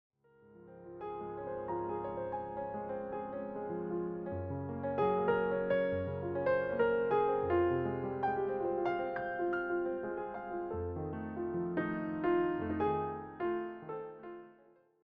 presented in a smooth piano setting.
steady, easygoing tone